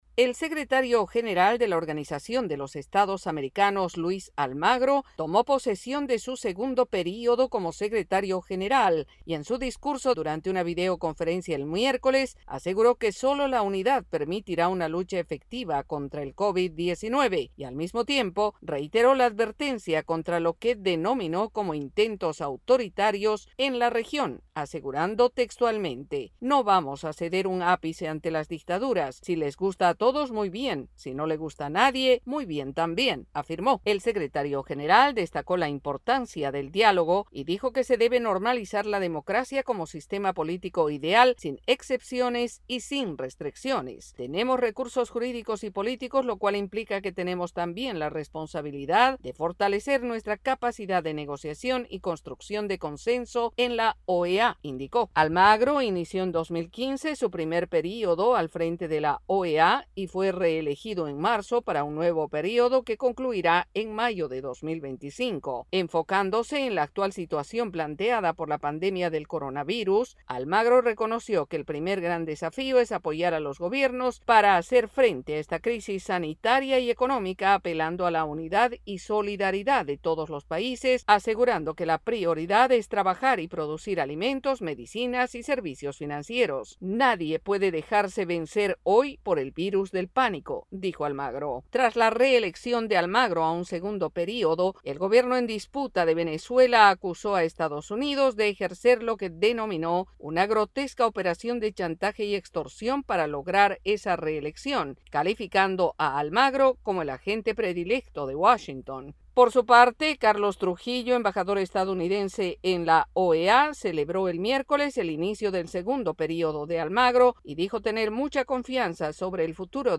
Luis Almagro asumió el segundo período como secretario general de la Organización de los Estados Americanos en una Asamblea virtual. El informe